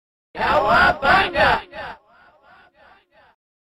cow.mp3